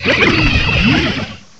cry_not_toxapex.aif